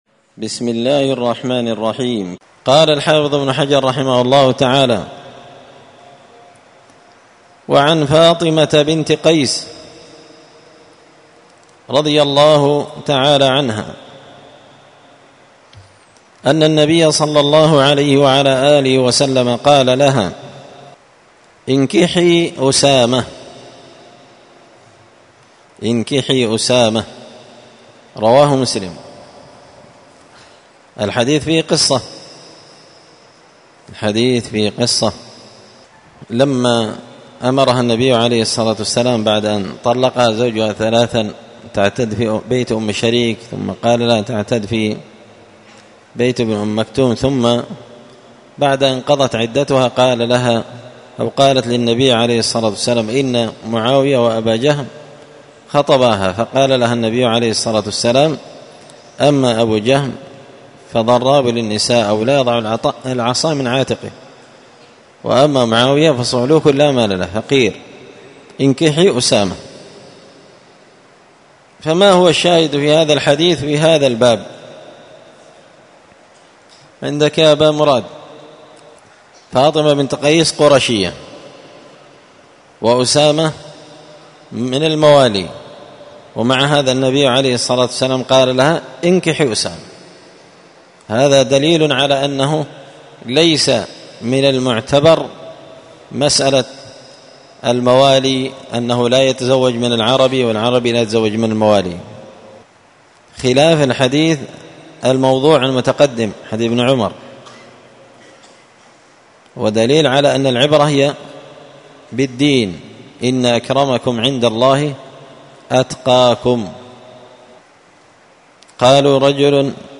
الدرس 16 تابع أحكام النكاح {باب الكفاءة والخيار}